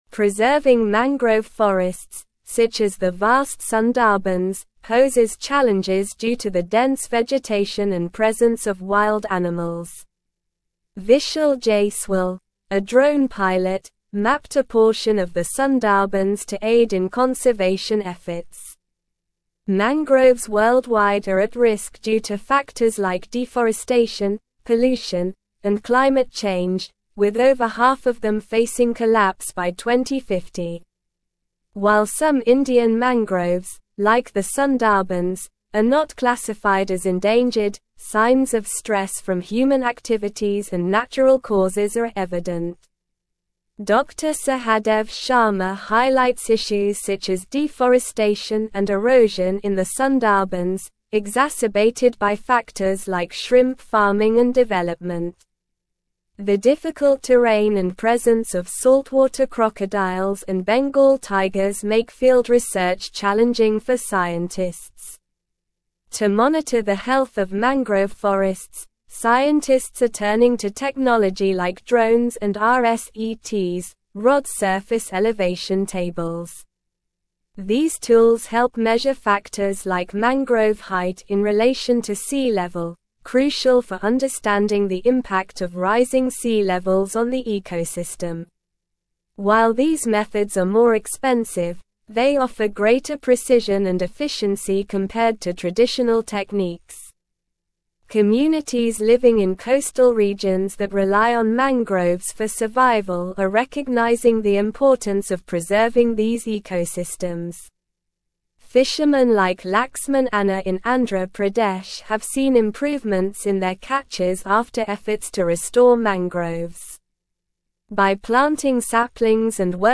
Slow
English-Newsroom-Advanced-SLOW-Reading-Mapping-Sundarbans-Drones-Preserve-Worlds-Largest-Mangrove-Forest.mp3